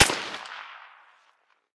heav_crack_08.ogg